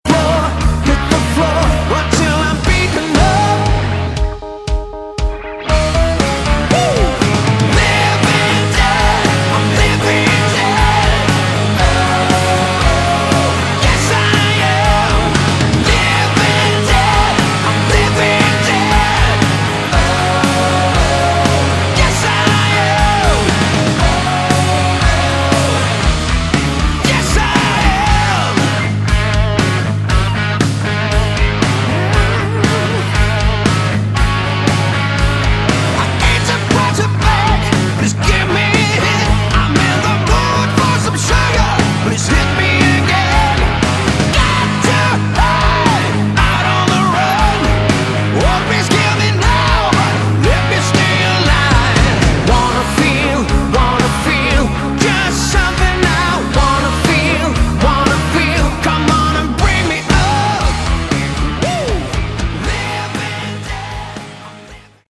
Category: Melodic Rock
lead and backing vocals, bass
guitars, synthesizers, backing vocals, percussion
drums
grand Piano, background vocals
organ